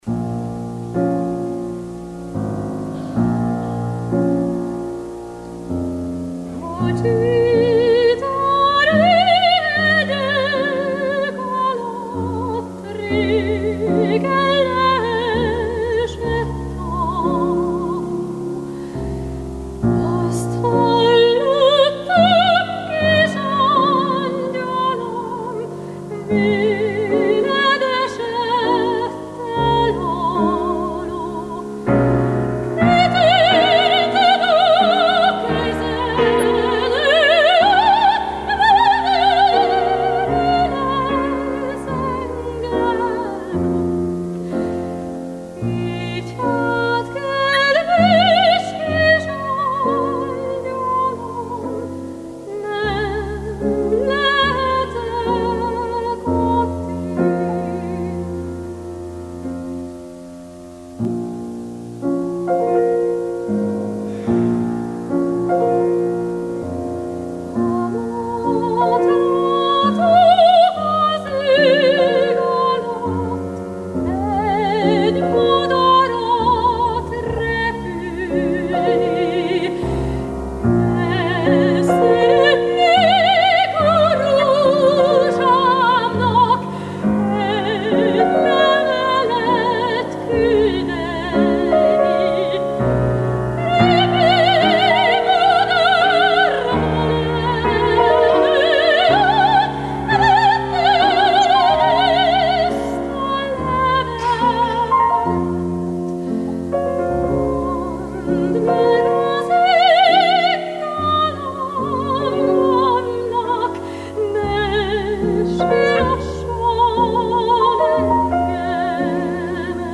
Az itt gyűjtött a Csitári hegyek alatt... c. népdal mára minden magyarlakta vidéken ismert, feldolgozásai révén nemzetközileg is jegyzik.